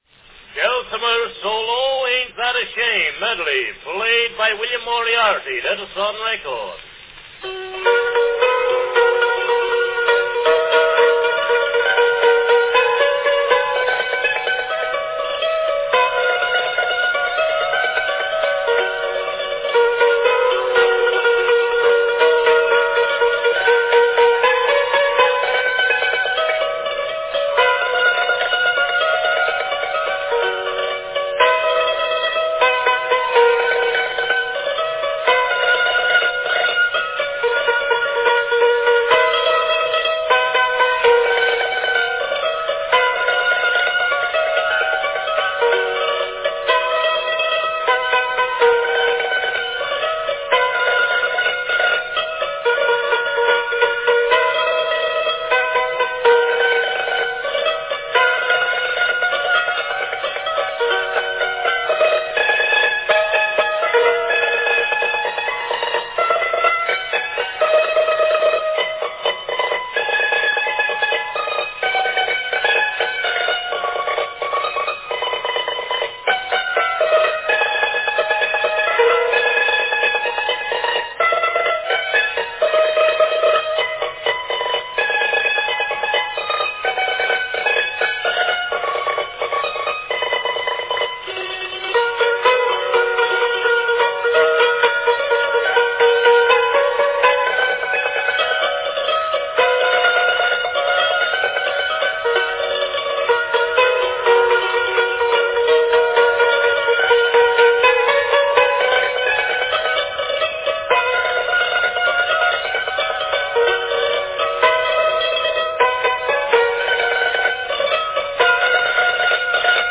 An early recording of the hammered dulcimer
Category Dulcimer solo
Here the hammered dulcimer gets its two minutes of fame.